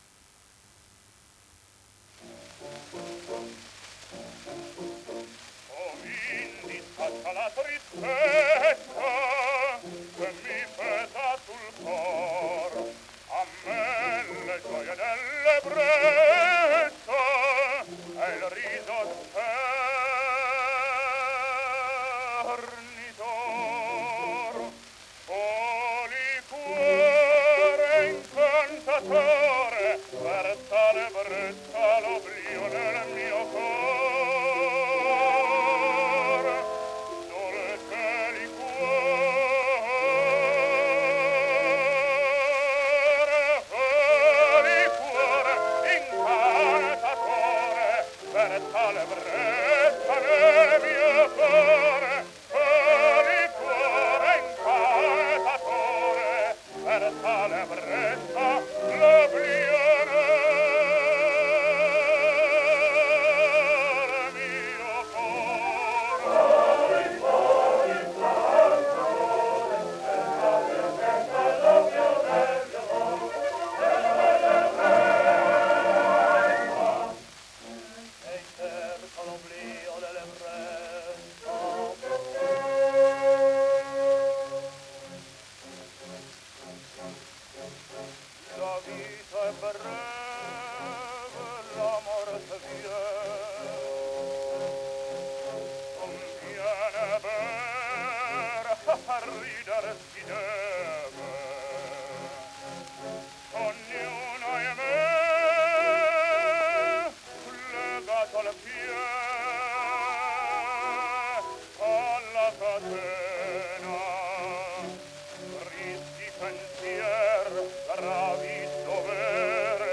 Sotto ascolterete tre brani da incisioni acustiche, e una registrazione dove scherza con la voce di Chaliapin.